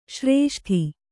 ♪ śrēṣṭhi